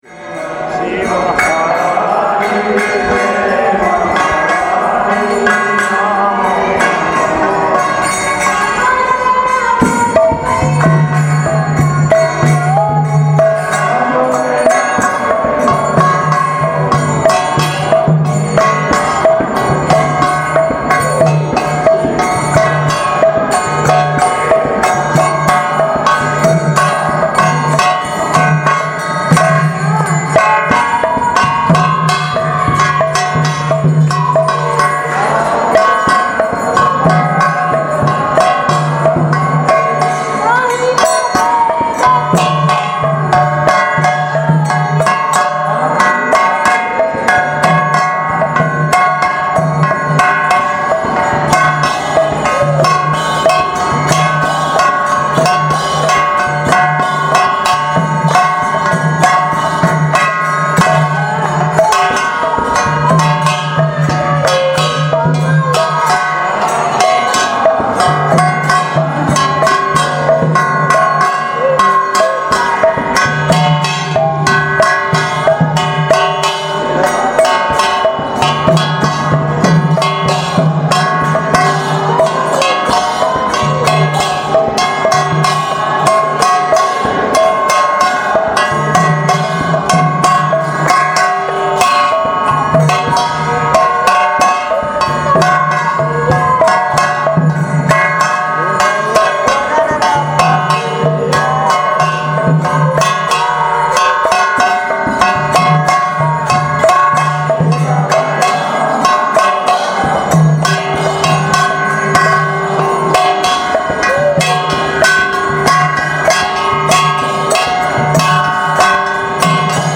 Place: Srila Sridhar Swami Seva Ashram Govardhan
Tags: Kirttan